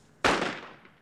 firework.mp3